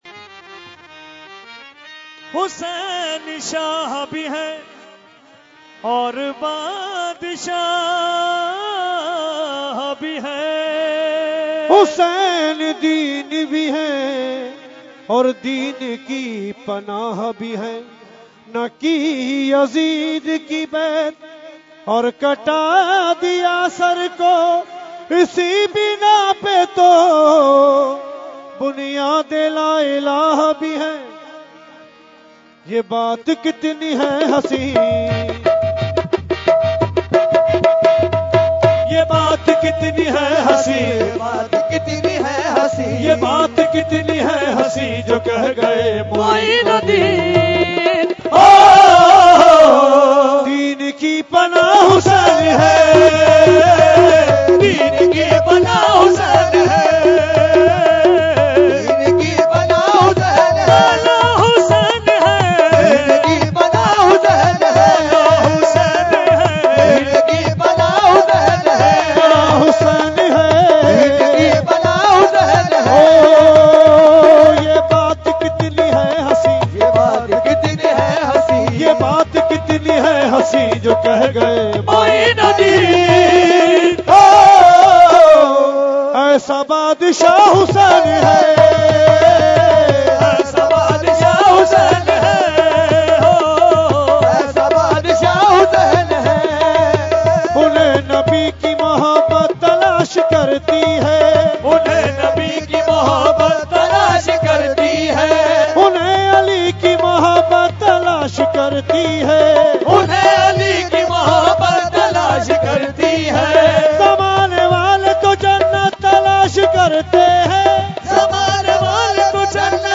Category : Qawali | Language : UrduEvent : Urs Makhdoome Samnani 2016